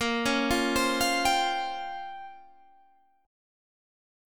A#m6add9 Chord
Listen to A#m6add9 strummed